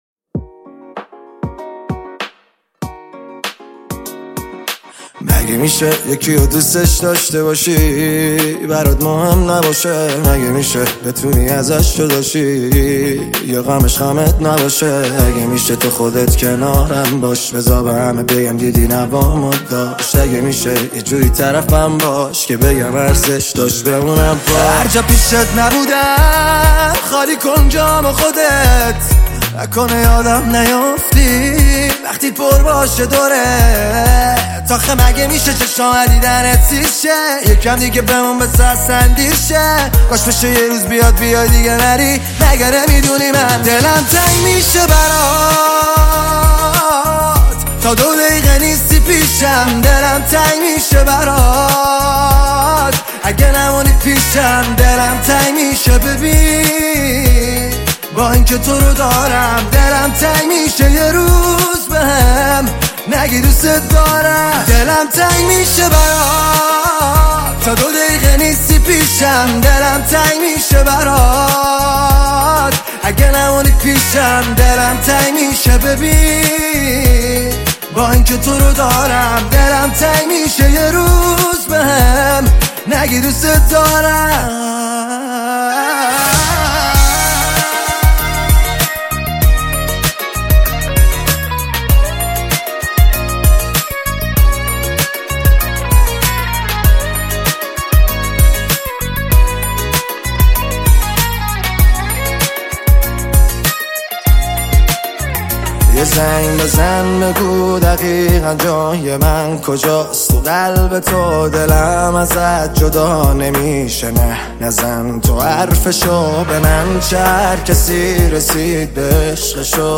پاپ عاشقانه عاشقانه غمگین